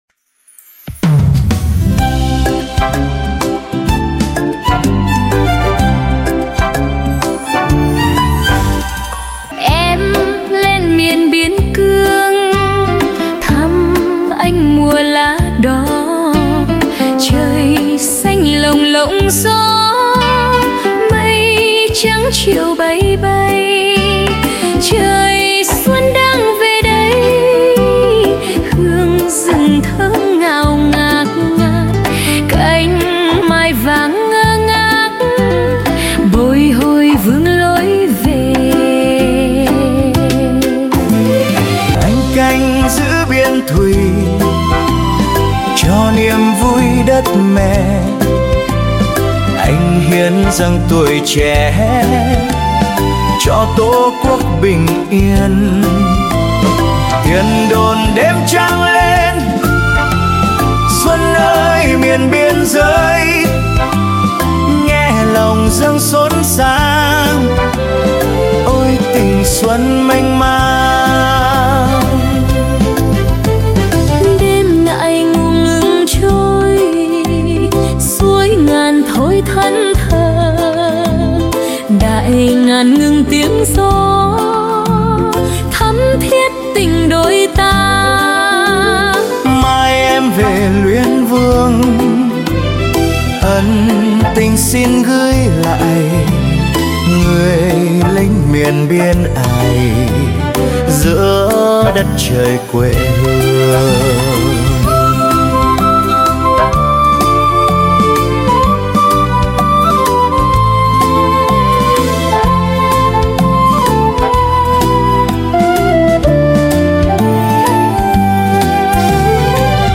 là một ca khúc mang đậm sắc thái trữ tình cách mạng